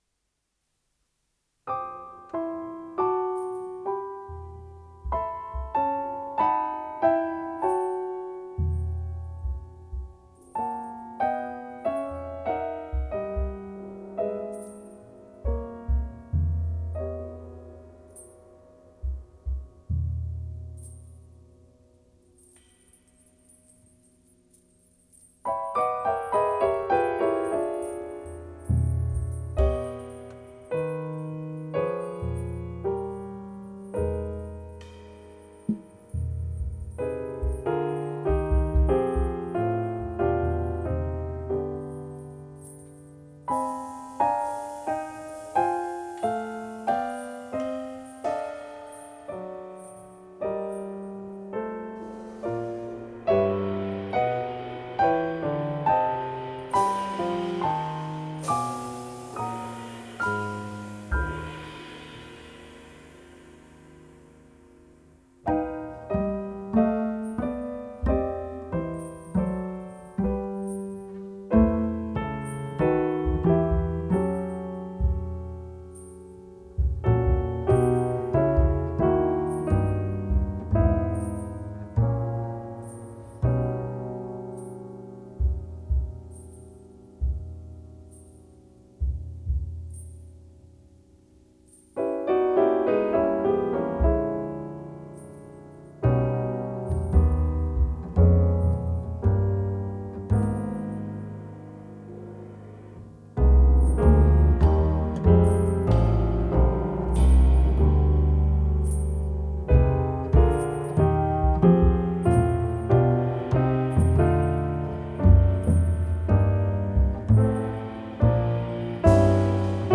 Klavier
Kontrabass
Schlagzeug